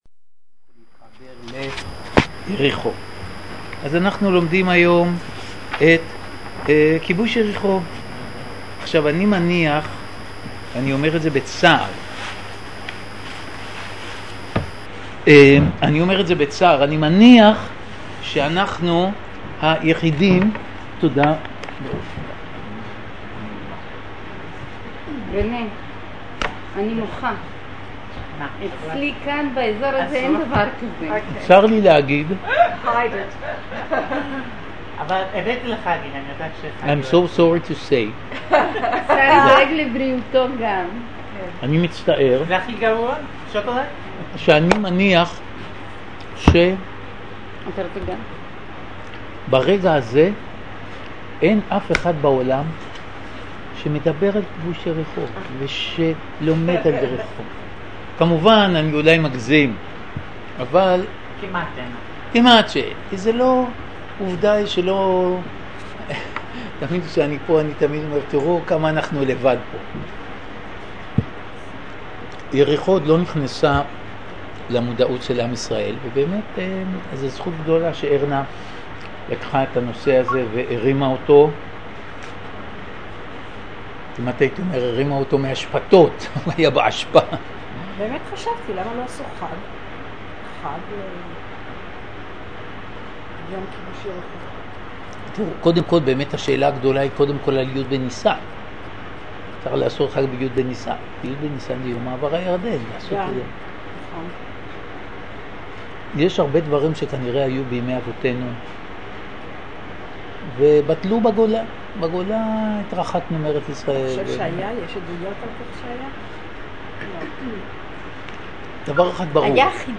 השיעור